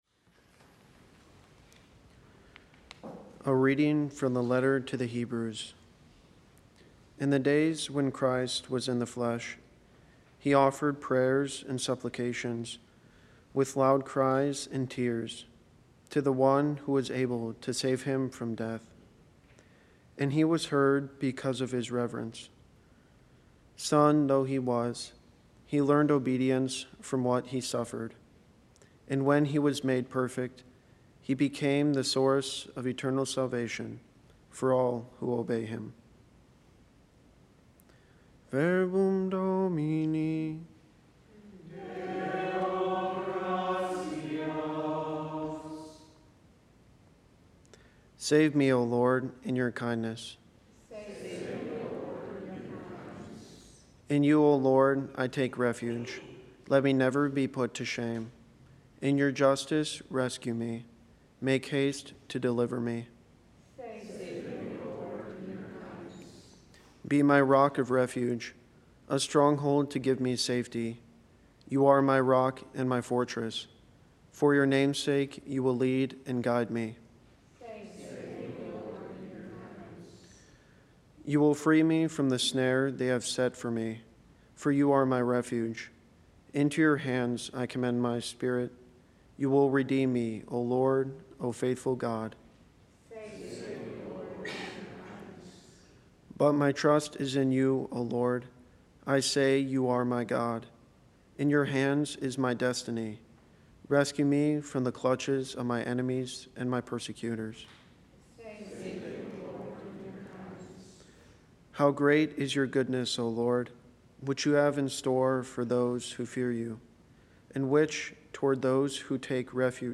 Readings and homily